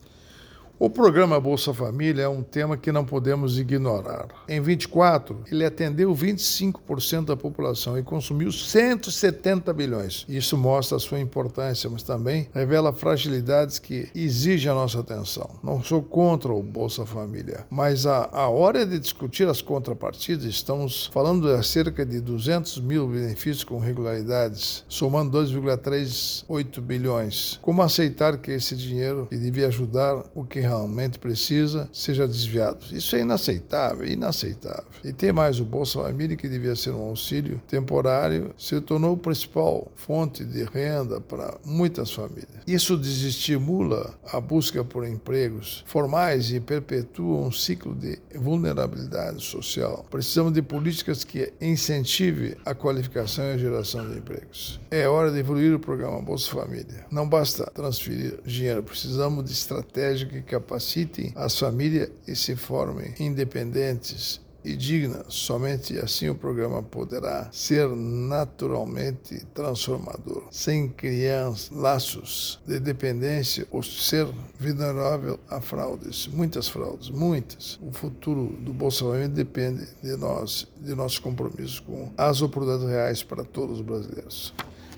Comentário de Augusto Nardes, ministro do Tribunal de Contas da União.